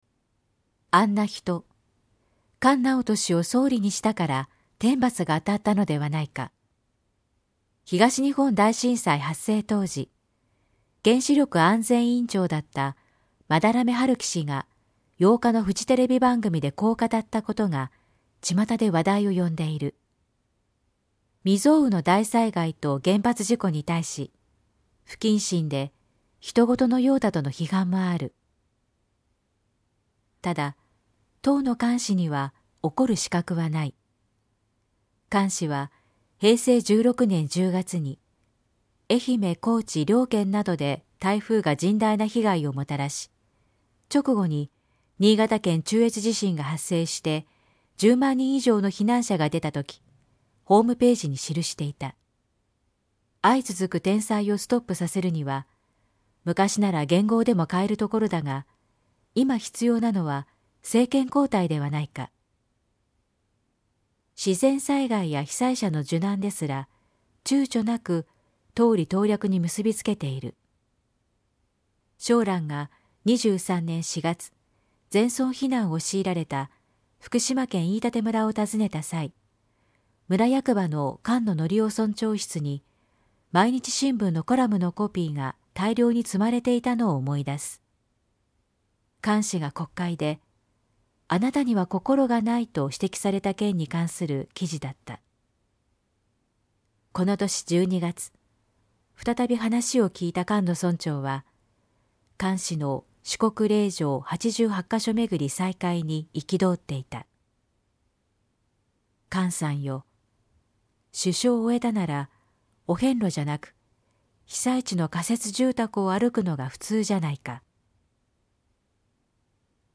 全国240名の登録がある局アナ経験者がお届けする番組「JKNTV」
産経新聞1面のコラム「産経抄」を局アナnetメンバーが毎日音読してお届けします。